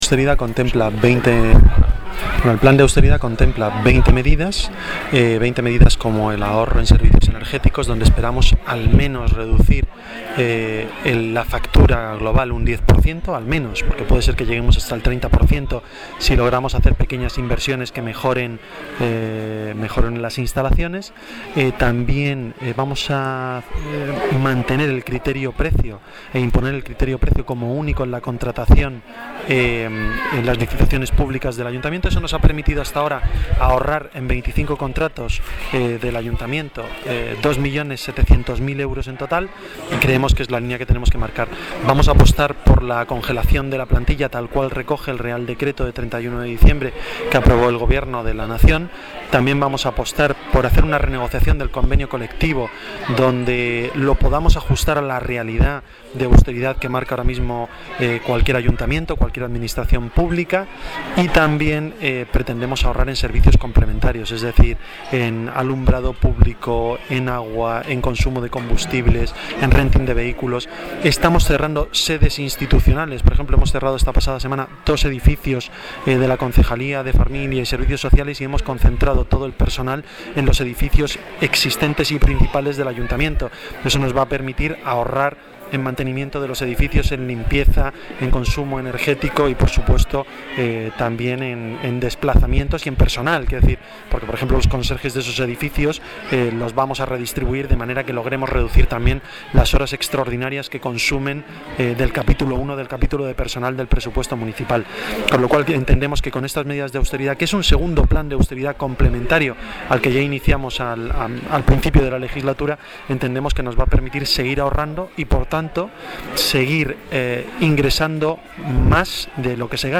Durante la visita a las nuevas aulas del colegio Ágora de Boadilla del Monte, Antonio González Terol habló para Diario de Boadilla acerca de las nuevas medidas que serán aprobadas por el Ayuntamiento y que irán en beneficio de todos los vecinos de Boadilla del Monte.
Escuche las declaraciones de Antonio González Terol